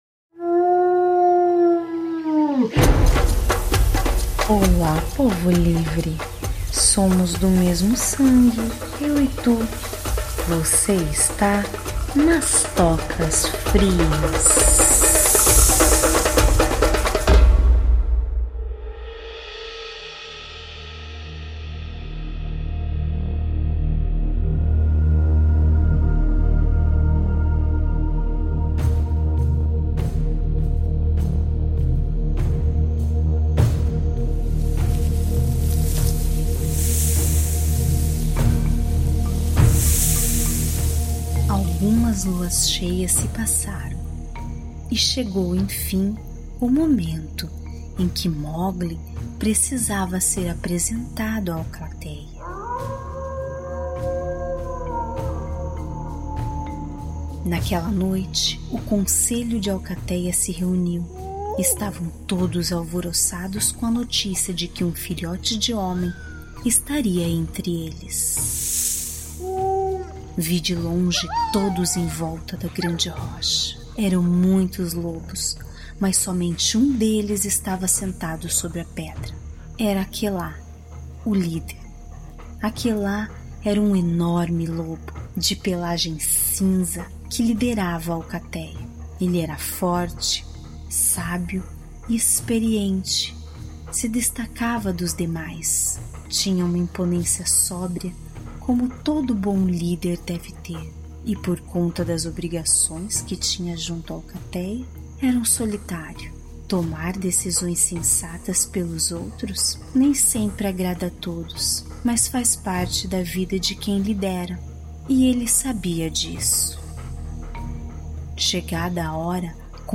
Que lindo!!! Adorei, parabéns pelo trabalho, edição, interpretação e dicção do sssssss